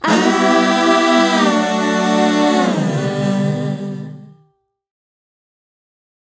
Aaah Group 086 2-G.wav